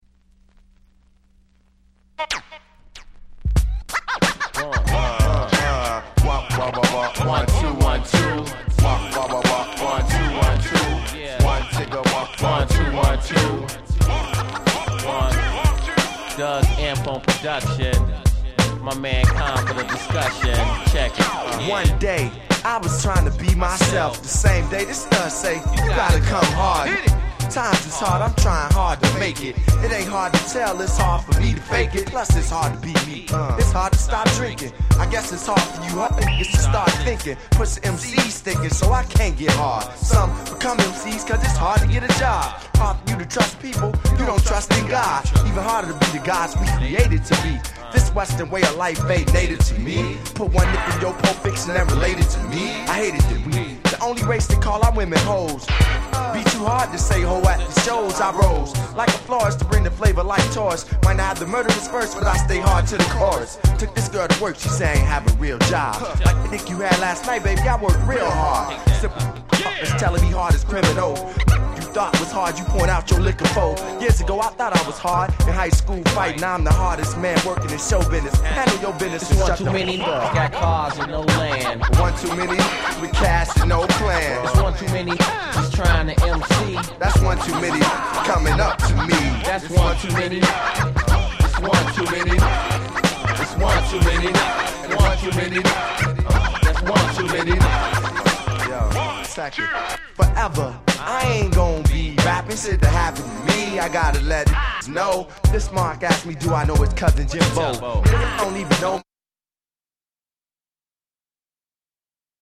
97' Smash Hit Hip Hop !!
90's Boom Bap ブーンバップ